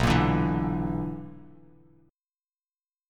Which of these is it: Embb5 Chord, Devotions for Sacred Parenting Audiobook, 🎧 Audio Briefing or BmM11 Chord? BmM11 Chord